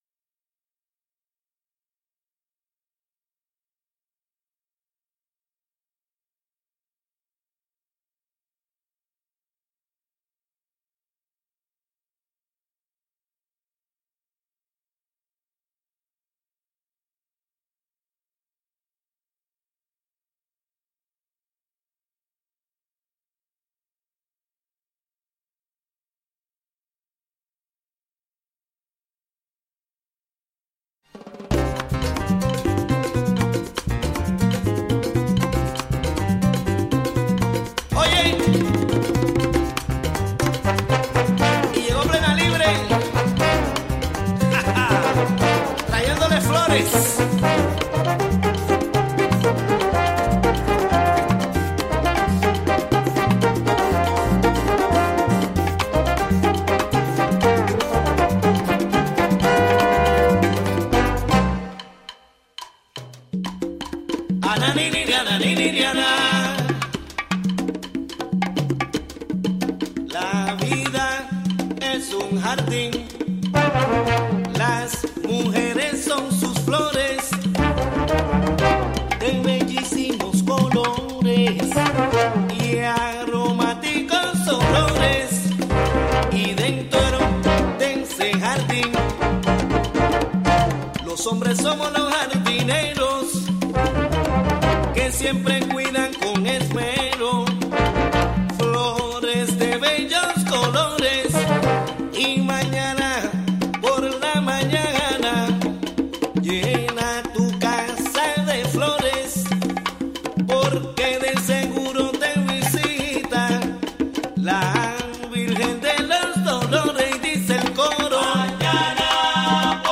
9am Un programa imperdible con noticias, entrevistas,...
Plus, a conversation about the March for Immigrant NY, in Kingston, on Sun., Dec. 18.